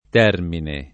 t$rmine] s. m. — con T- maiusc. come nome del dio romano dei confini — rara l’ant. variante termino [t$rmino], modellata sul classico lat. terminus — sim. i top. e cogn. Termine, Termini — tra i top., Termini Imerese (Sic.) e stazione di Termini (a Roma), l’uno e l’altro per antico accostam. dell’originario terme al pl. di termine — cfr. Imera